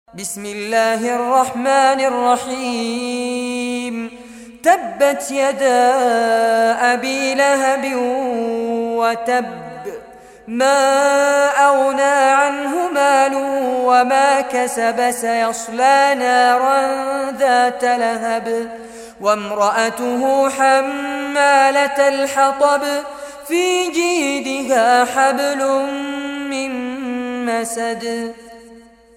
Surah Al-Masad Recitation by Fares Abbad
Surah Al-Masad, listen or play online mp3 tilawat / recitation in Arabic in the beautiful voice of Sheikh Fares Abbad.
111-surah-masad.mp3